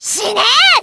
Worms speechbanks
Watchthis.wav